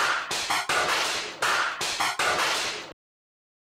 HIT BOY FX.wav